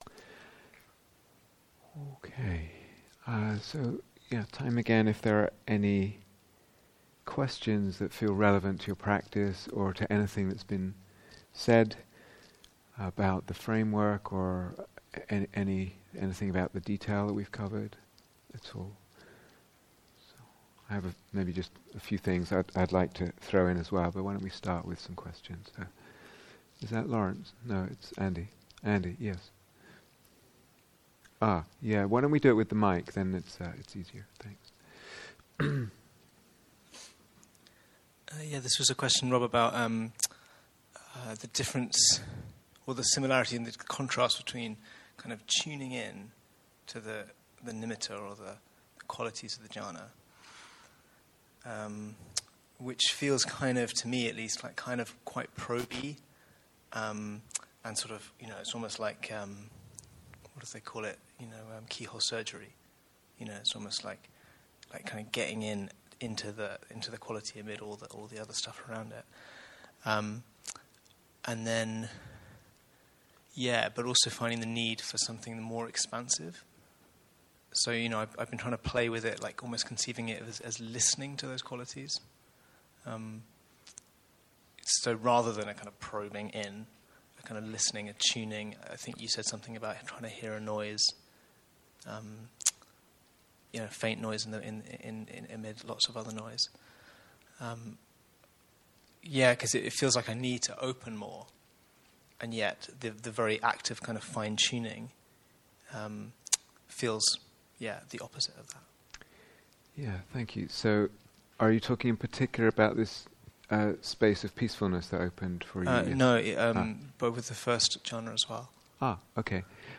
Q & A, and Short Talk